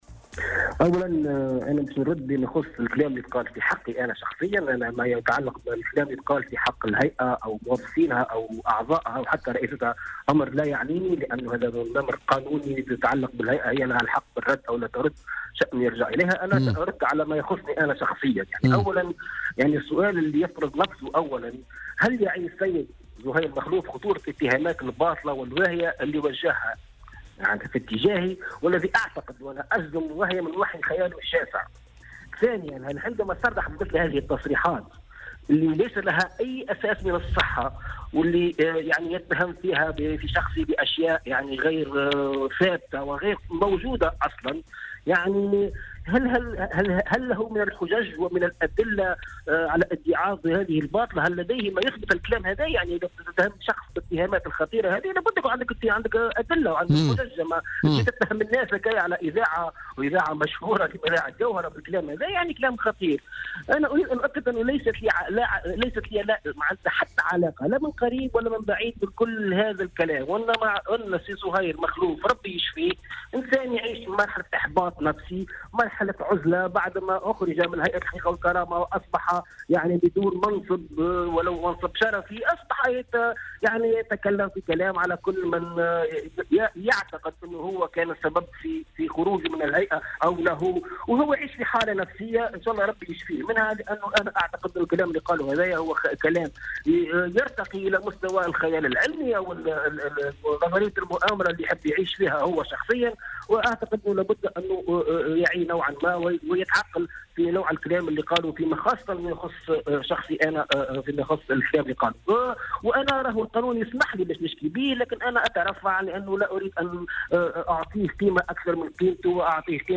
وأضاف في مداخلة له اليوم في برنامج "بوليتيكا"